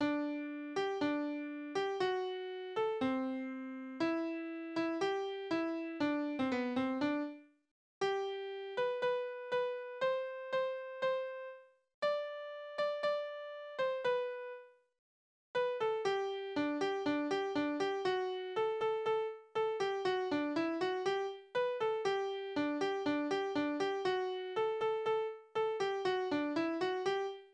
Liebeslieder:
Tonart: G-Dur
Taktart: 4/4
Tonumfang: große Dezime
Besetzung: vokal